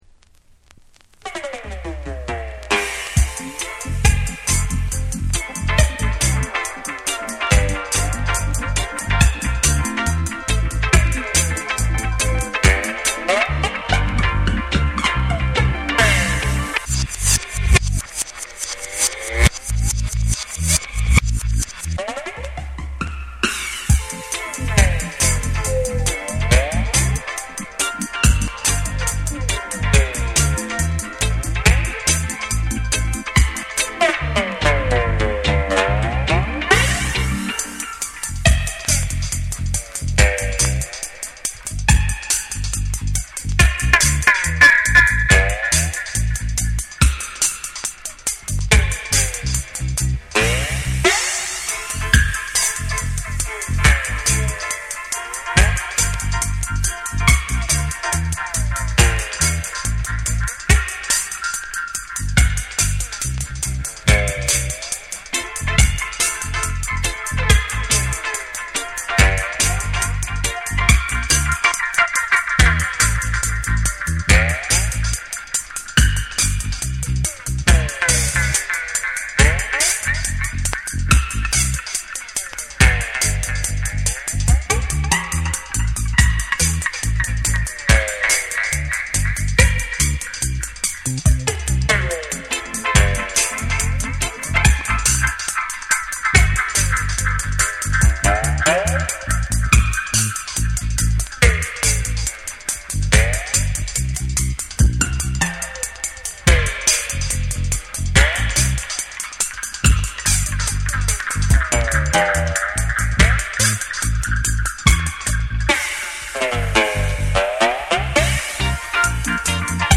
ヘヴィなステッパーズ・リズムを軸に、深く沈み込むベースとエコー／リバーブを効かせた空間的なミックスが際立つダブを収録。
REGGAE & DUB